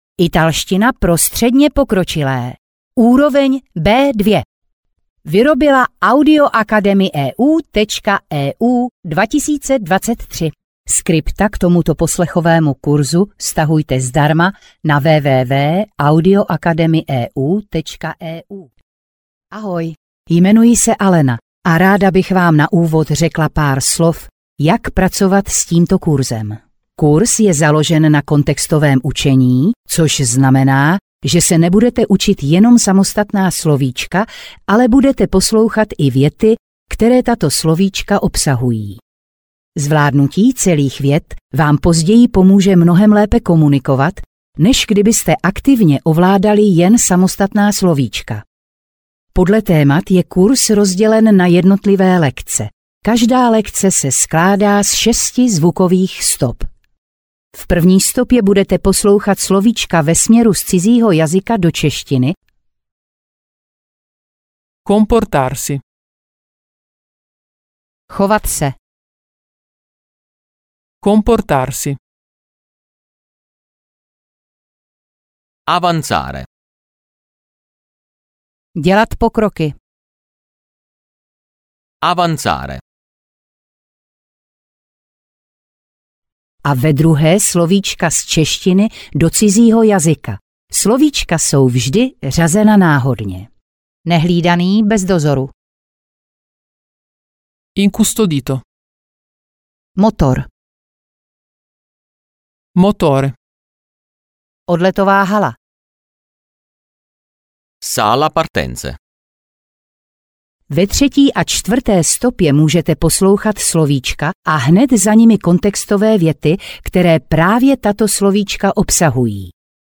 Vyberte Audiokniha 329 Kč Další informace